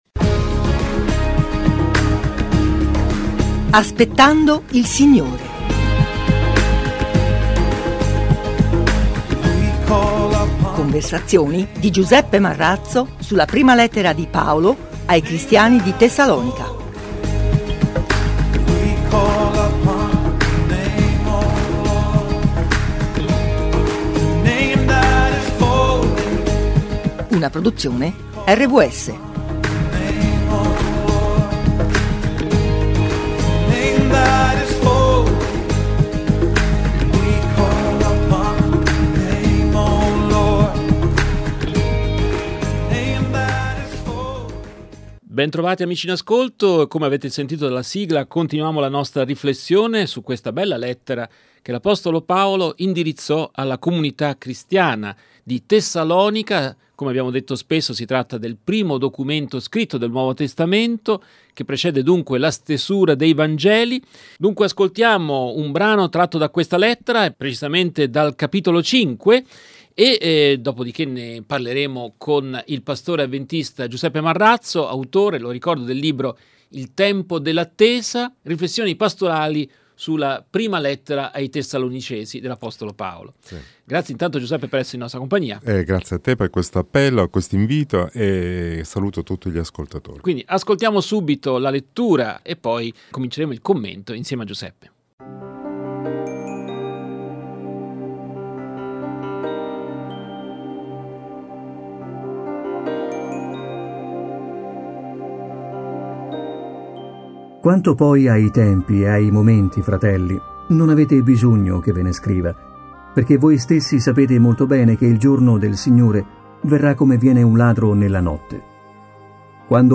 “Aspettando il Signore” e’ una serie di conversazioni radiofoniche